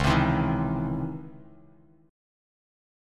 C#mM9 chord